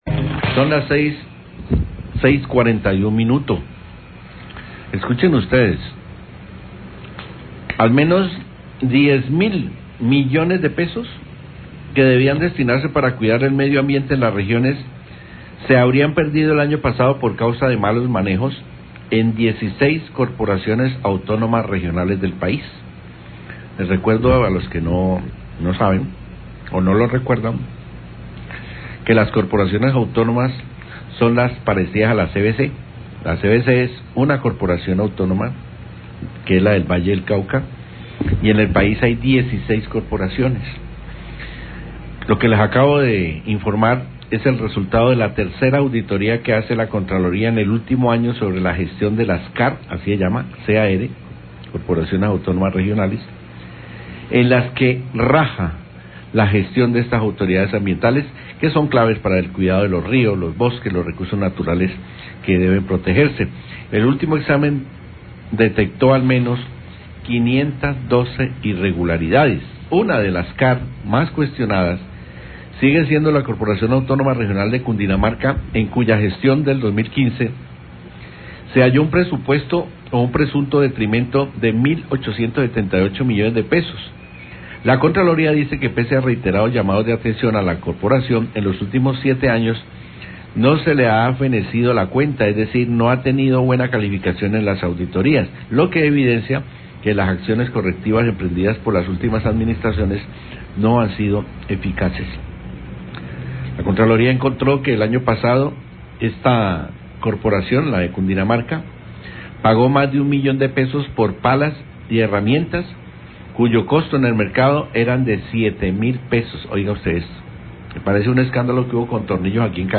Radio
NOTICIERO RELÁMPAGO
La Contraloría General de la Nación prersentó un informe sobre la auditoría a la gestión de las Corporaciones Autónomas Regionales donde se detectó varias irregularidades en algunas CAR. Periodista destaca que la CVC no se encontraron irregularidades.